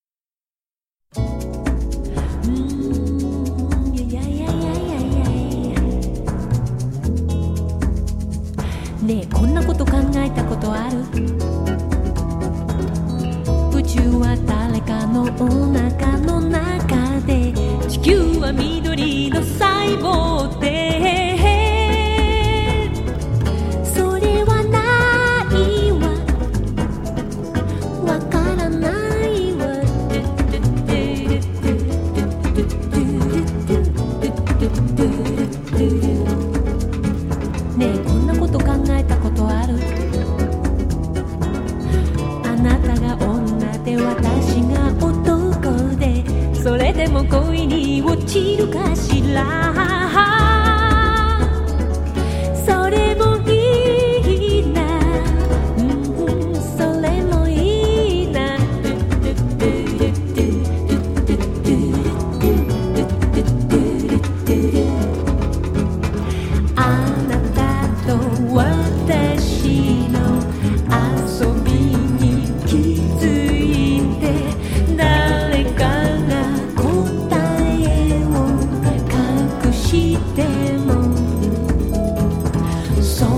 風ラップ+メロウ・ボサ